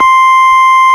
Index of /90_sSampleCDs/Keyboards of The 60's and 70's - CD1/ORG_FarfisaCombo/ORG_FarfisaCombo
ORG_VIP Pwr2 C_6.wav